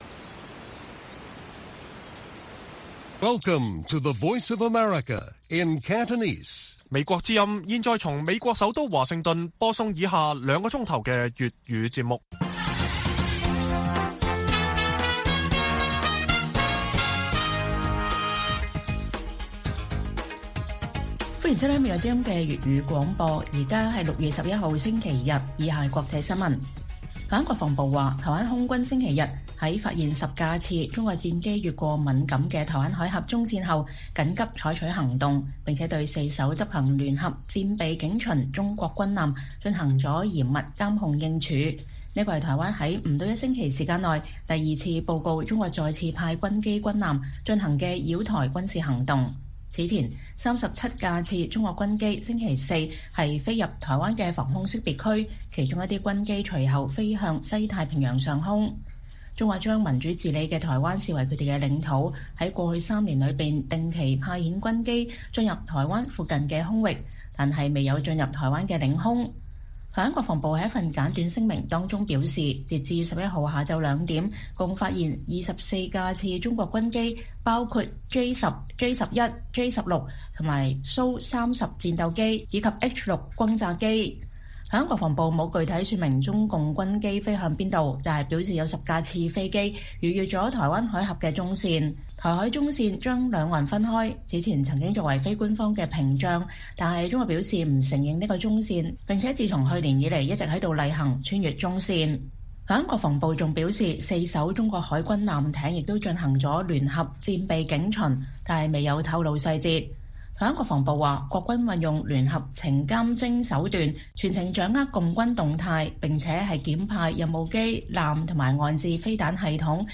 粵語新聞 晚上9-10點：中國週日再次擾台 10架次軍機飛越台海中線